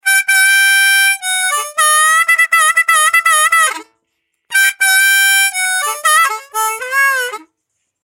口琴 " 卡吉恩小调全集
描述：在iPod touch上使用Hohner Blues Harp。这是我玩的东西，我称它为Cajun Lick.
标签： 印第安 竖琴 蓝调 音乐 仪器 乐器 口琴 C 记录
声道立体声